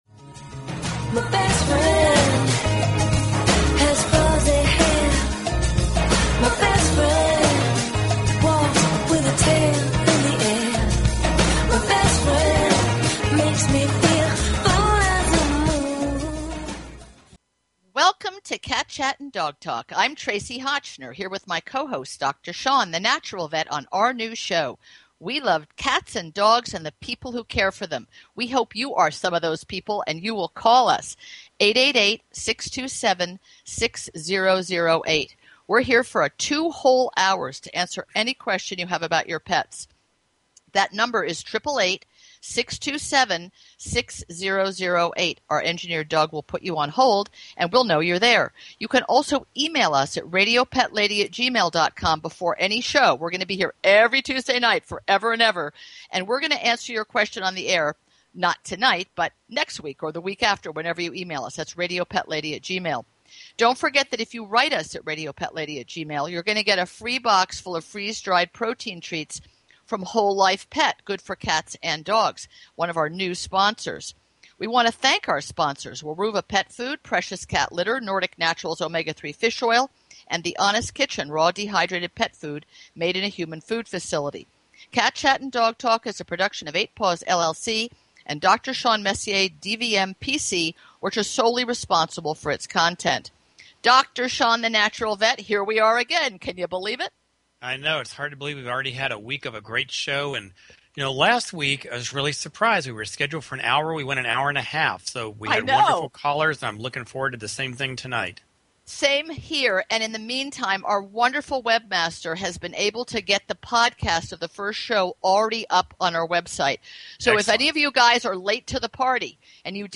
Talk Show Episode, Audio Podcast, Cat_Chat_and_Dog_Talk and Courtesy of BBS Radio on , show guests , about , categorized as